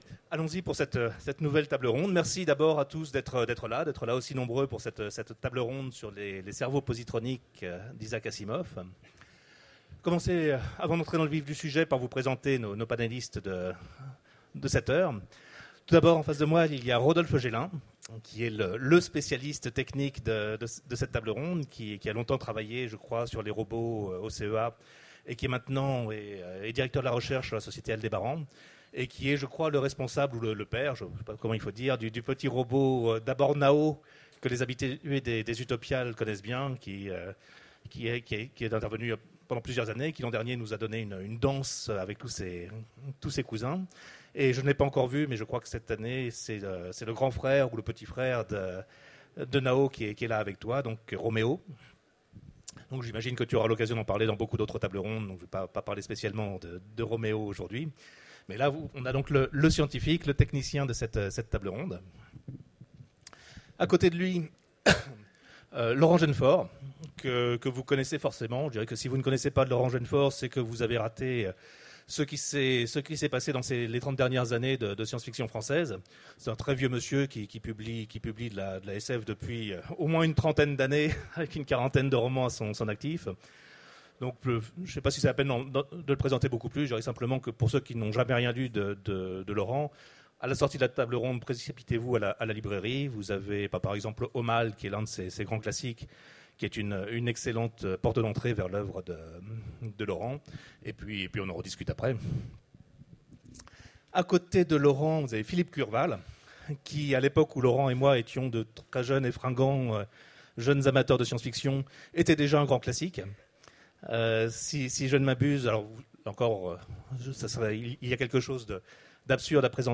Mots-clés Asimov Robot Conférence Partager cet article